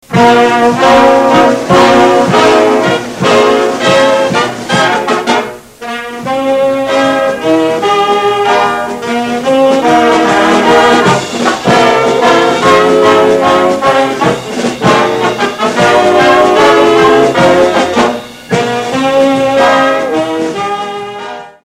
Gattung: Foxtrott
Besetzung: Blasorchester